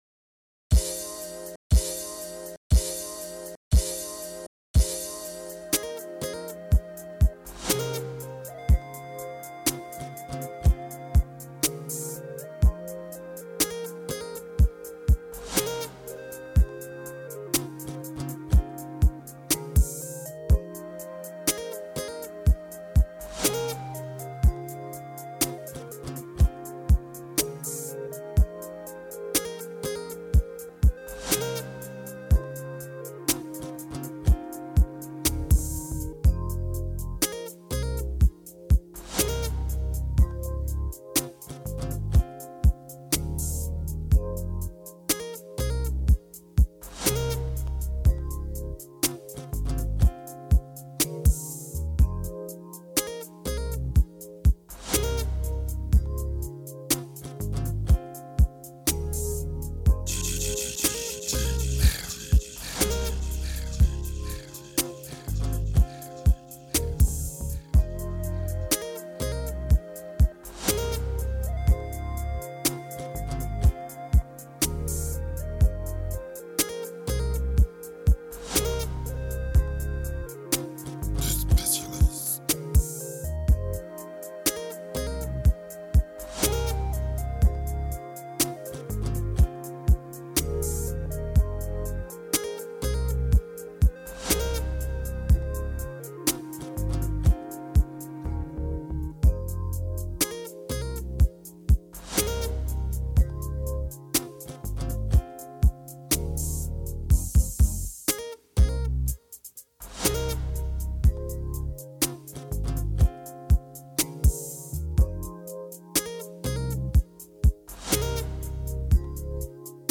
Cette semaine, texte et son smooth... Enjoy !